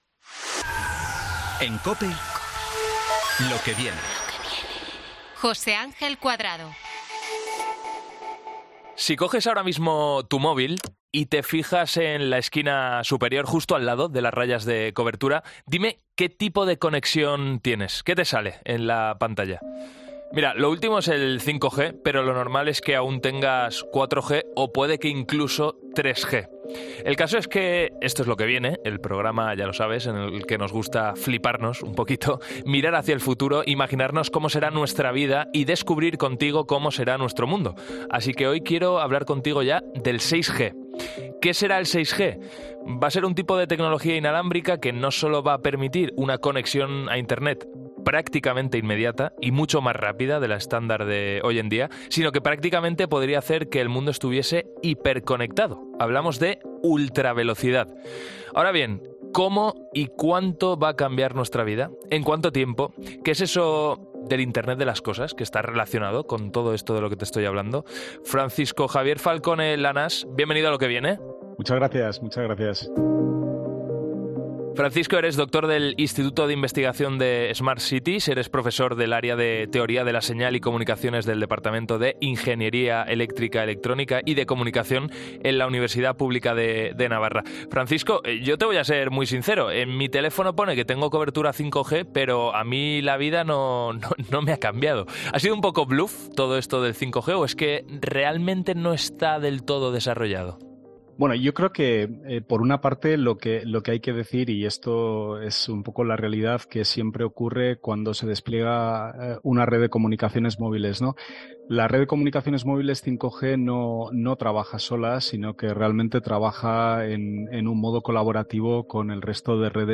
Ya lo sabes, apunta el 2030 en el calendario, así lo explicaba en COPE